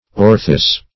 Orthis synonyms, pronunciation, spelling and more from Free Dictionary.
orthis.mp3